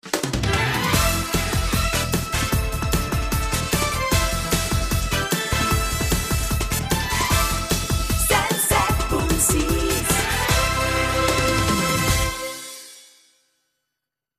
Indicatiu amb la freqüència d'emissió.